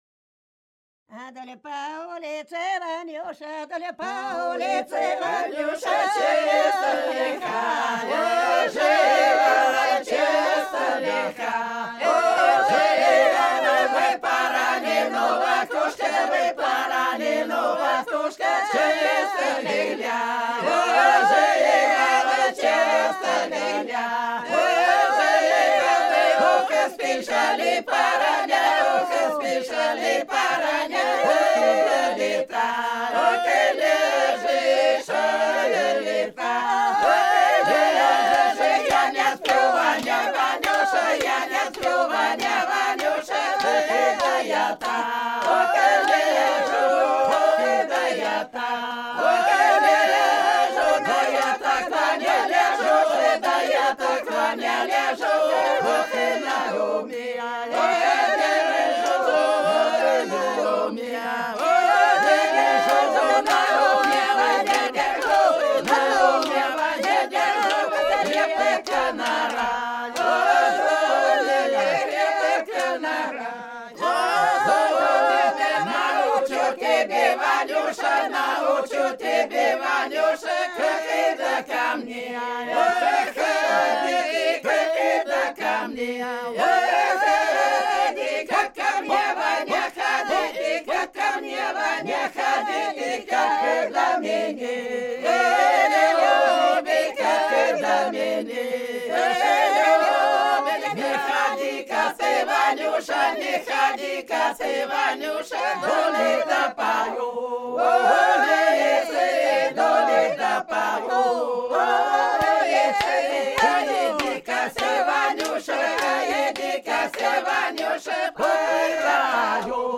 Ансамбль села Хмелевого Белгородской области Вдоль по улице Ванюша часто хаживал (улишная, плясовая на расход гостей, осенью)